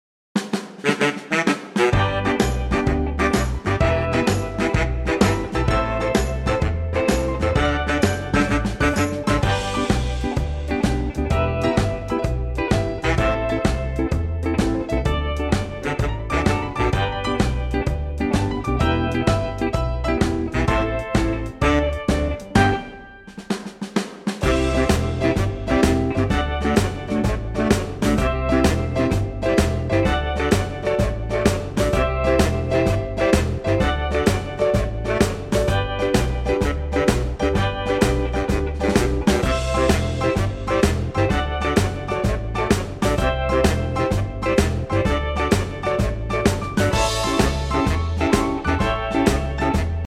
Unique Backing Tracks
key G
4 bar intro and vocal in at 9 secs
in a female key.
key - G - vocal range - B to A
for a female vocal.